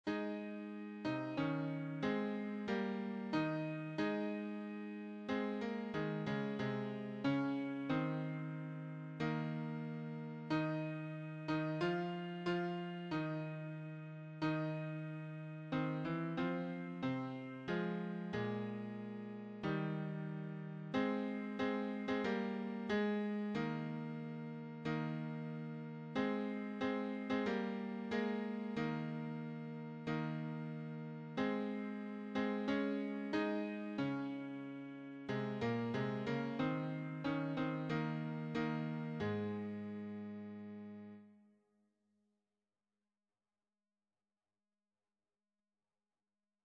choir SATB
Sacred choral songs